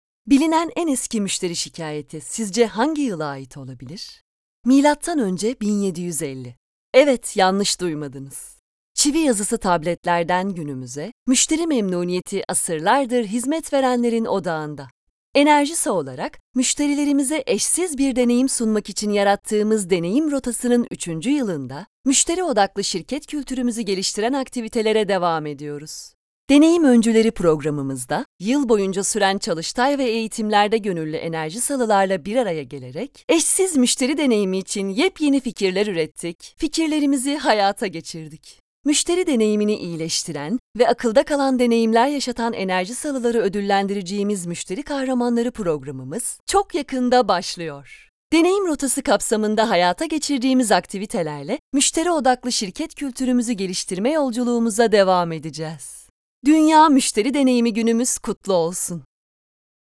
Profundo, Natural, Cool, Cálida, Empresarial
Corporativo
She has her own professional home studio.